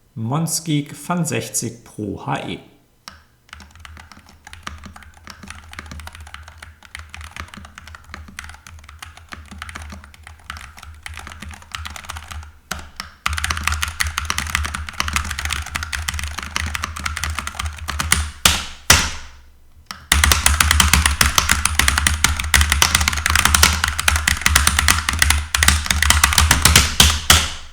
Akustik: Könnte leiser
Sie gibt dank zwei Schaumstoff-Schichten Dämmung ein klar besseres Bild ab als absolute Einstiegsmodelle wie die SGK25 von Sharkoon, bleibt aber recht präsent. Anschläge sind recht hell und ein wenig verwaschen, vor allem eine Prise Kunststoff-klackrig.
Besonders leise ist die Fun60 insgesamt nicht, für ihre Preisklasse aber durchaus akzeptabel.